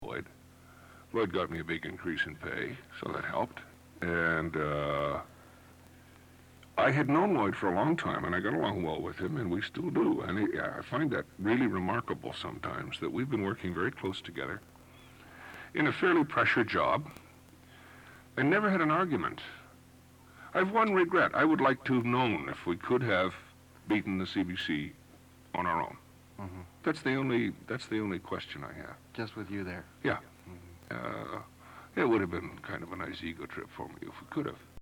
I interviewed Harvey on my CFRB program before he died in Feb of 2002 on the occasion of the publication of his own memoir ” Nobody calls me Mr. Kirk “, and although he told me that he and Lloyd got along very well, he did regret never having the opportunity to know if he could have beaten the CBC on his own.